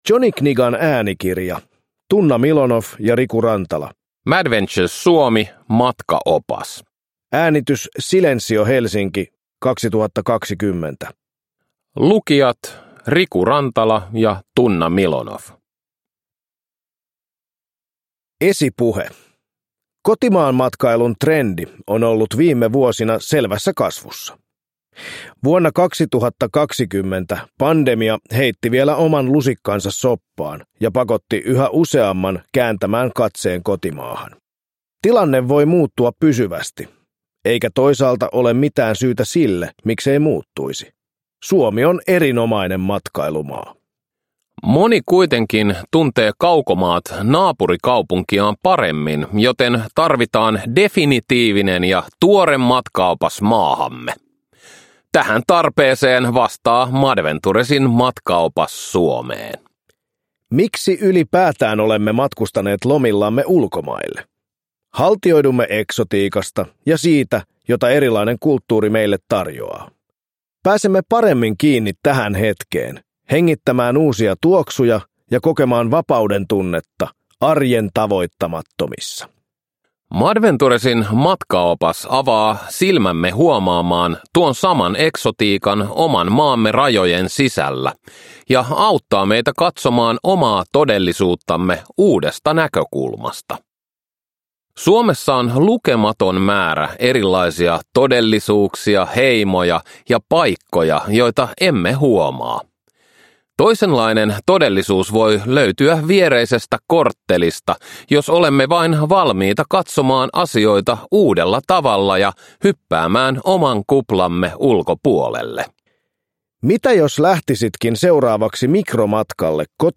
Madventures Suomi – Ljudbok
Uppläsare: Tuomas Milonoff, Riku Rantala